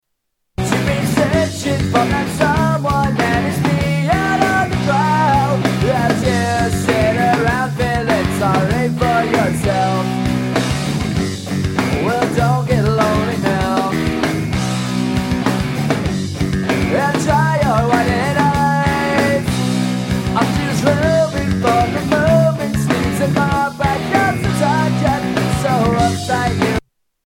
Tags: Sound Effects Rock Truetone Ringtones Music Rock Songs